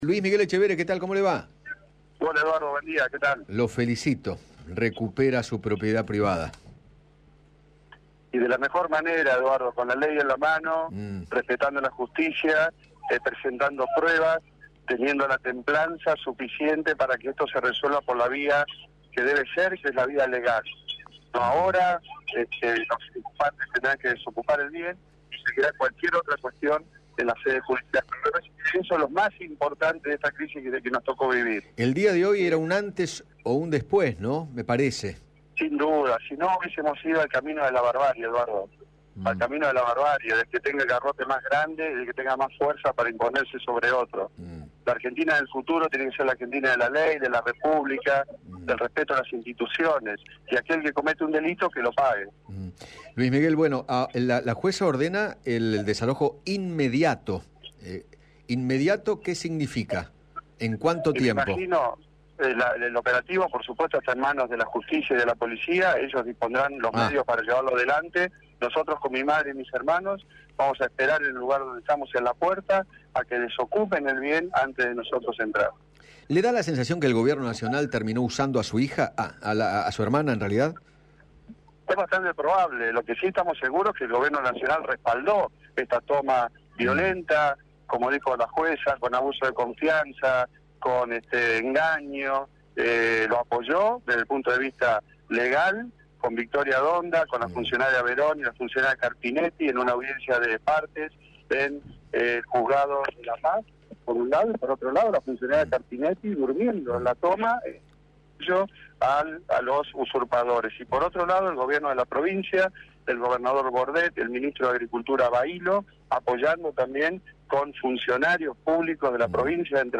Luis Miguel Etchevehere, ex ministro de Agroindustria, habló con Eduardo Feinmann acerca de la resolución de la jueza Carolina Castagno, quien ordenó el “reintegro en forma inmediata” de su campo en Entre Ríos.